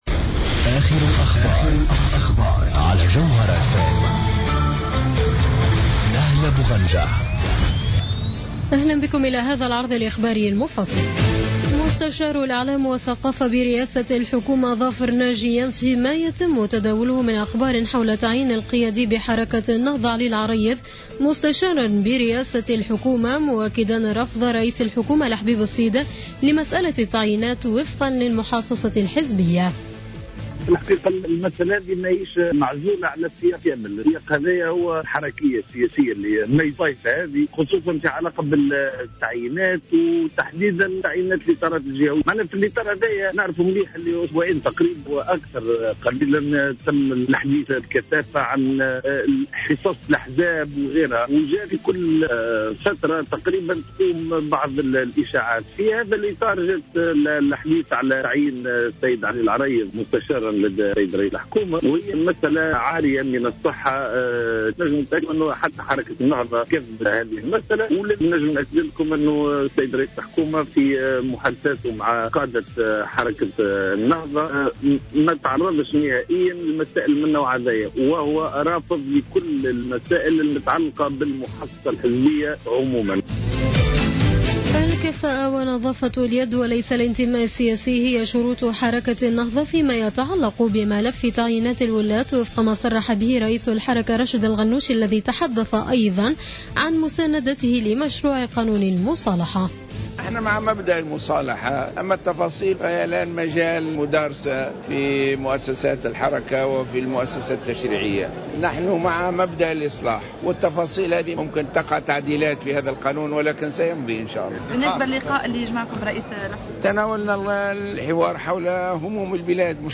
نشرة أخبار منتصف الليل ليوم الإثنين 10 اوت 2015